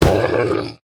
Minecraft Version Minecraft Version 1.21.5 Latest Release | Latest Snapshot 1.21.5 / assets / minecraft / sounds / mob / wolf / angry / hurt3.ogg Compare With Compare With Latest Release | Latest Snapshot
hurt3.ogg